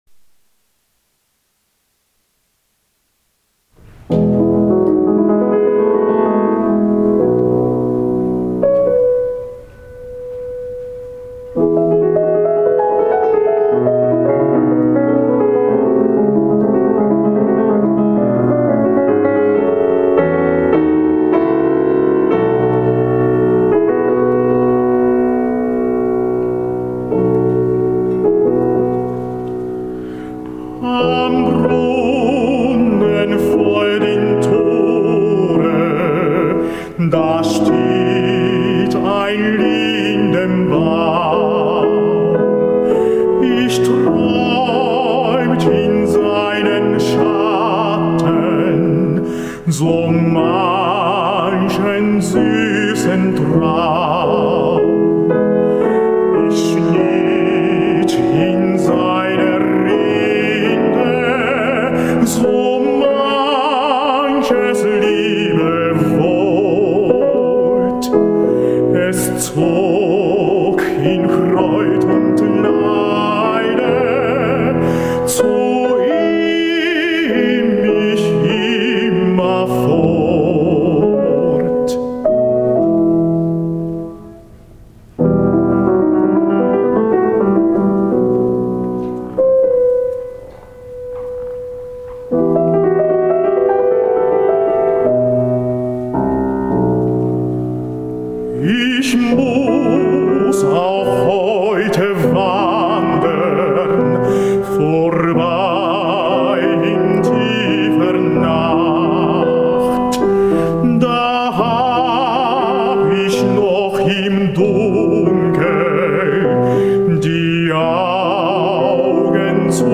歌曲集
ﾃﾉｰﾙ